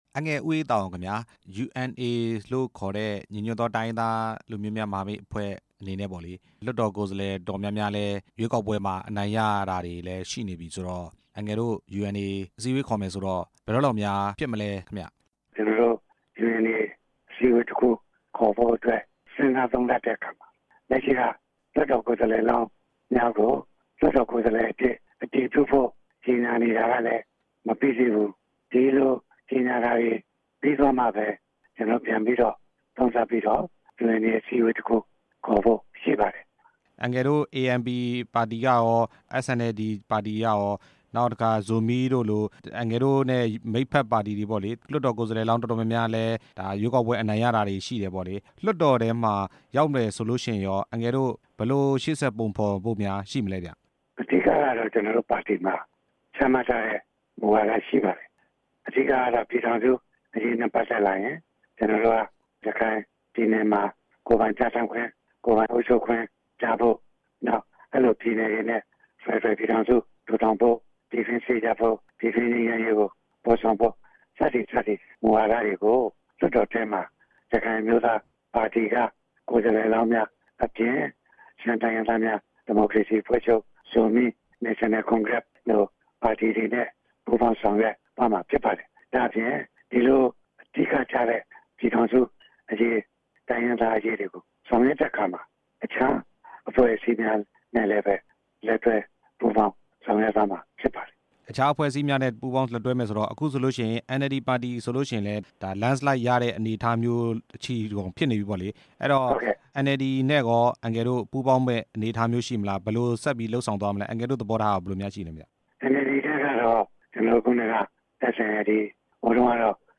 ရခိုင်အမျိုးသားပါတီက ဦးအေးသာအောင်နဲ့ မေးမြန်းချက်